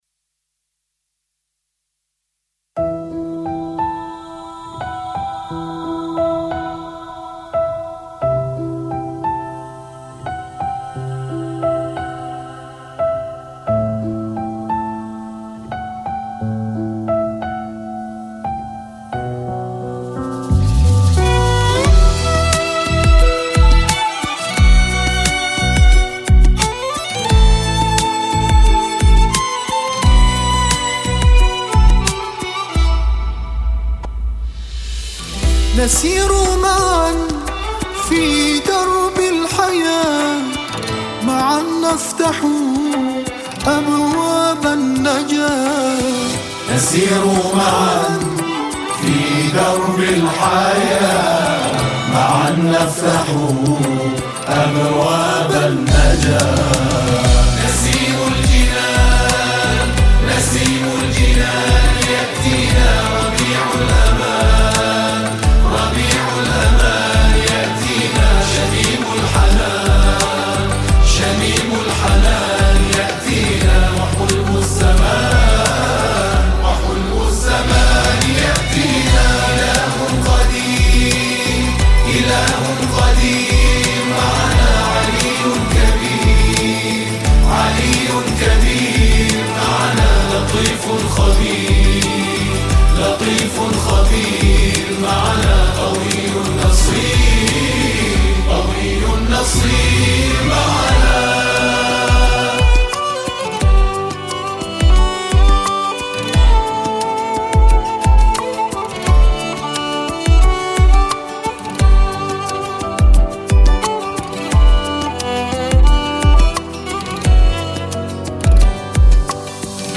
، ربیع الاول ، نماهنگ مذهبی